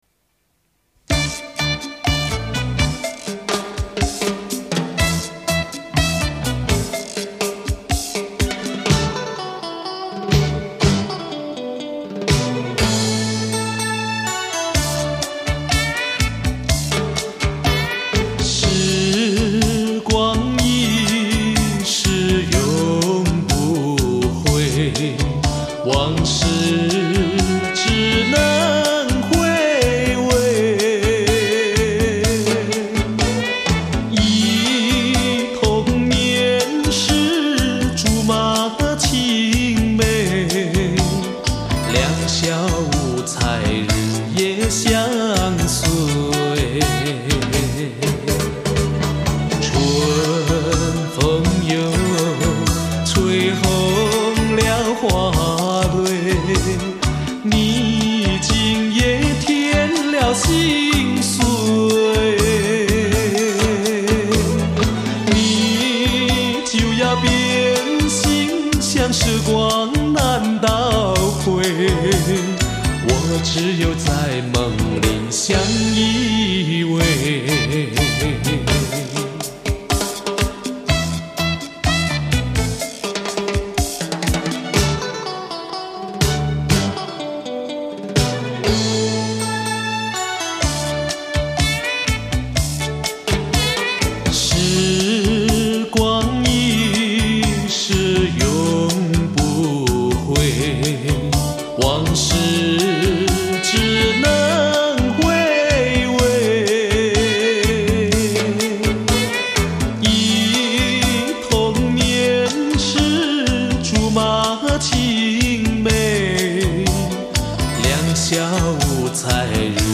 因为这个专辑的歌曲有联唱的衔接